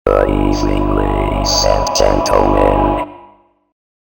S – GOOD EVENING LADIES & GENTLEMEN – COMPUTER
S-GOOD-EVENING-LADIES-&-GENTLEMEN-COMPUTER.mp3